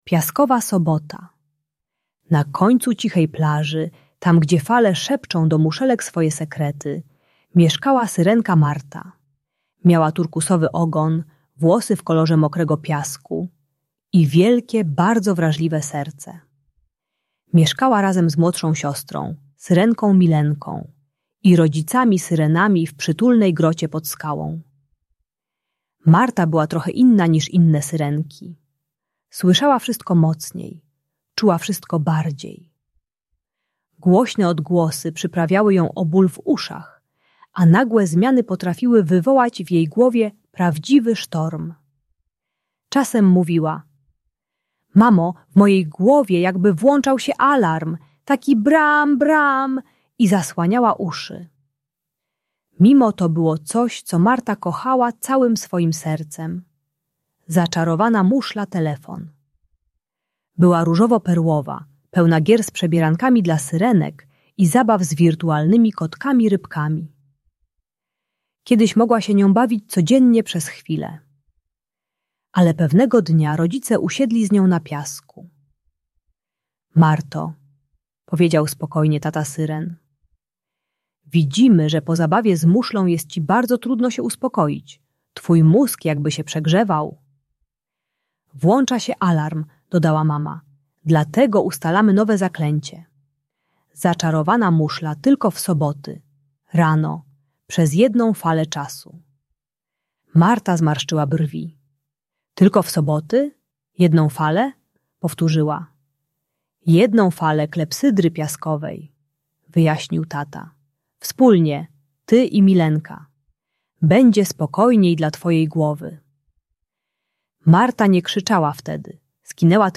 Audiobajka o uzależnieniu od telefonu i tabletu dla dzieci 4-7 lat. Uczy techniki "Trzech Oddechów Fali" - głębokiego oddychania, które pomaga uspokoić się gdy kończy się czas z elektroniką.